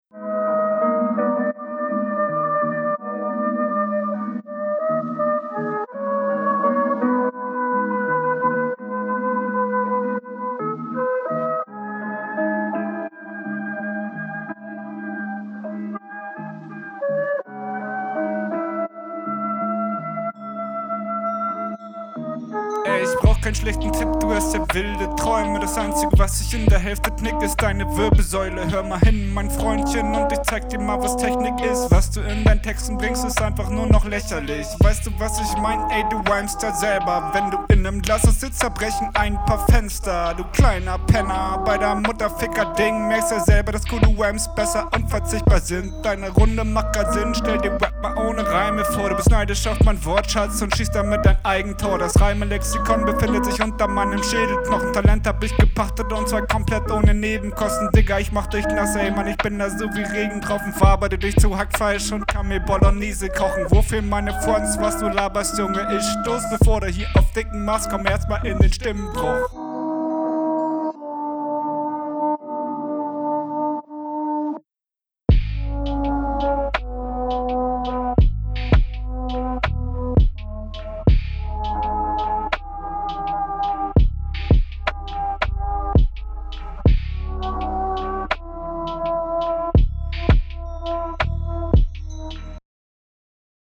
Deine Runde wirkt sehr gehetzt und ist nicht ganz so gut geflowt wie die deines …
Du ziehst manche Betonungen in die länger um den Reim aufrecht zu halten.